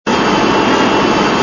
BSG FX - Viper Engine 04 Cruising
BSG_FX-Viper_Engine_04_Cruising.mp3